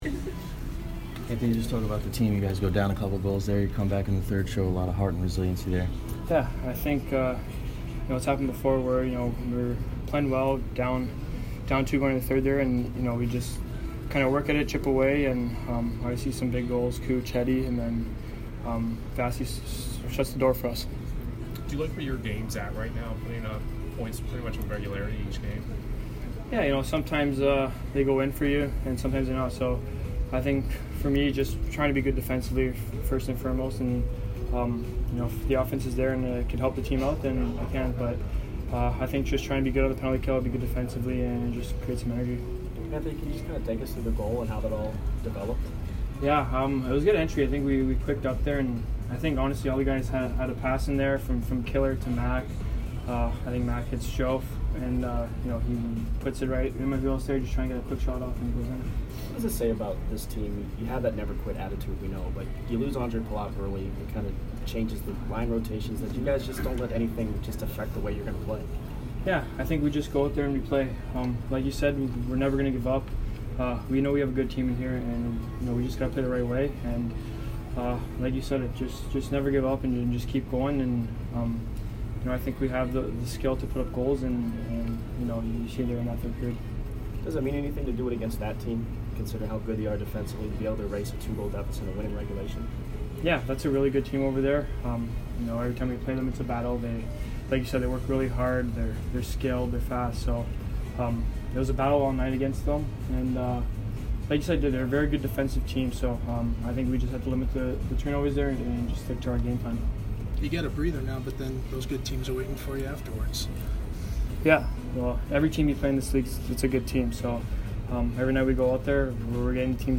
Anthony Cirelli post-game 3/25